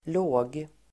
Uttal: [lå:g]